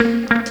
RIFFGTR 11-L.wav